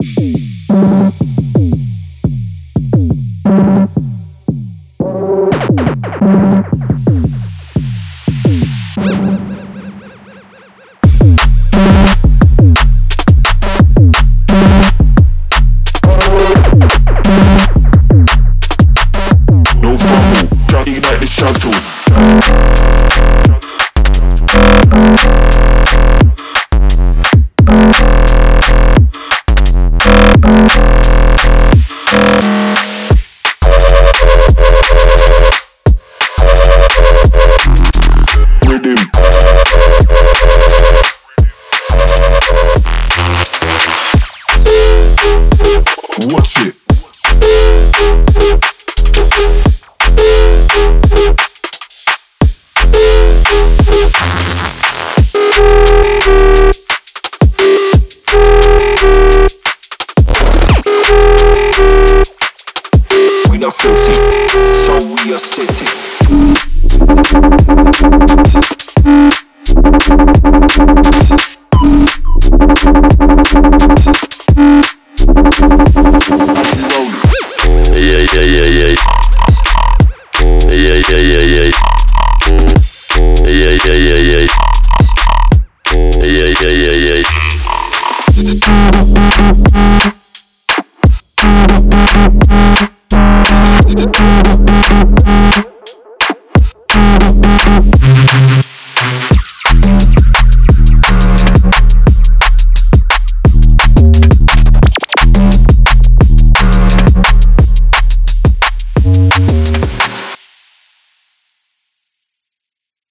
Genre:Drum and Bass
大胆で、重厚で、最高の形で混沌を引き起こすために作られています。
デモサウンドはコチラ↓